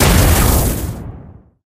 enrager_ulti_hit_02.ogg